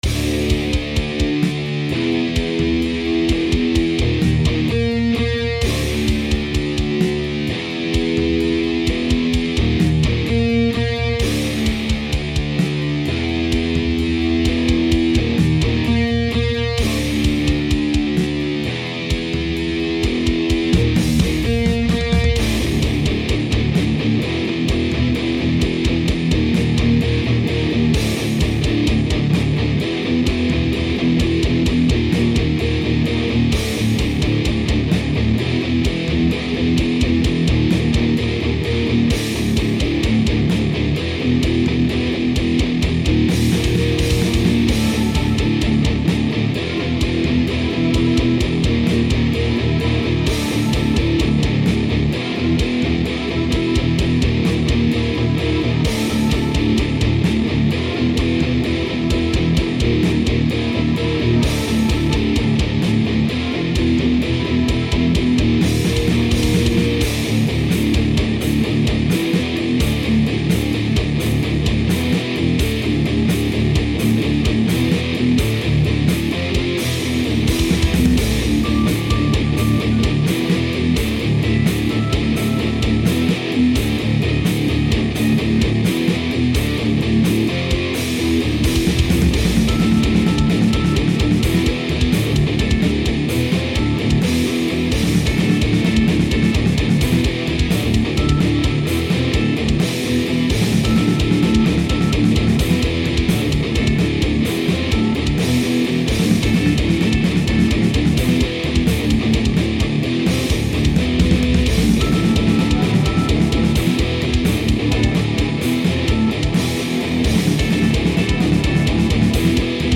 Кажется, удалось найти нужное звучание. Похоже это действительно готик-сладж. Shreddage 2, Sr rock bass, плагин Vawes, драмсы из сэмплов